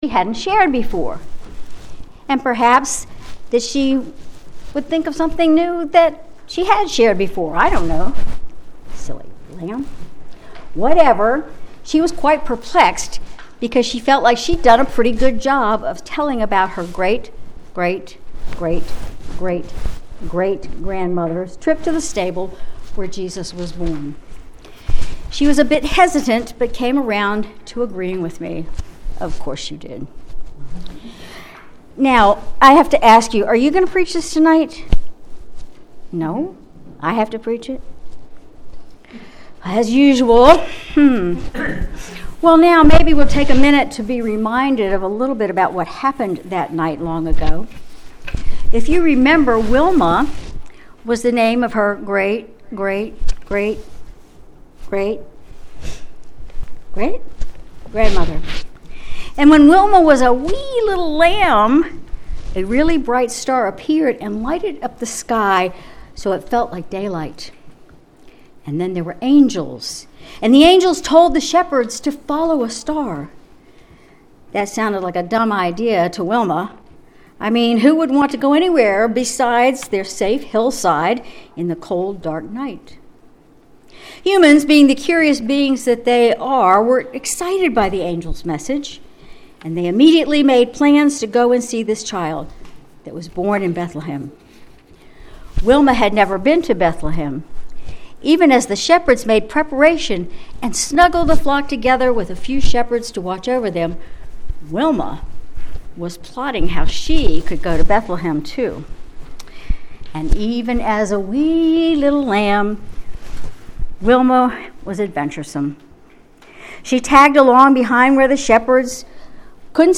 Sermon December 24, 2023 Christmas Eve